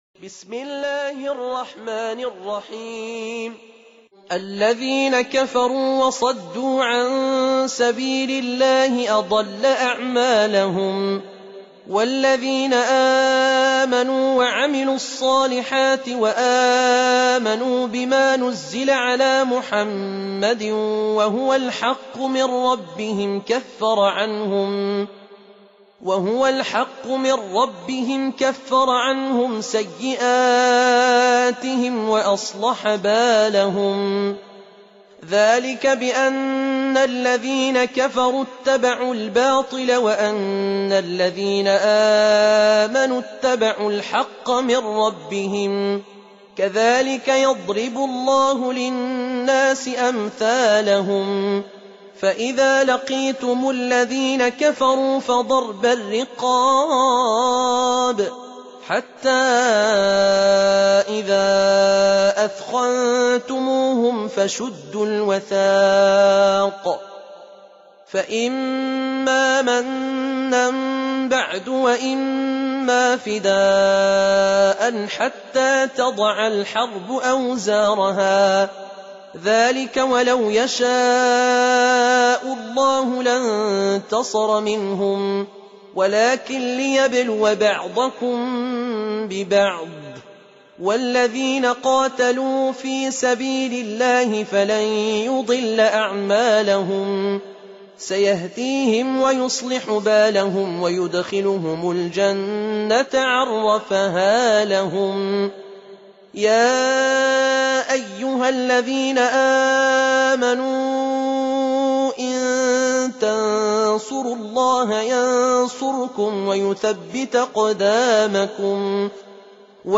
Surah Sequence تتابع السورة Download Surah حمّل السورة Reciting Murattalah Audio for 47. Surah Muhammad or Al-Qit�l سورة محمد N.B *Surah Includes Al-Basmalah Reciters Sequents تتابع التلاوات Reciters Repeats تكرار التلاوات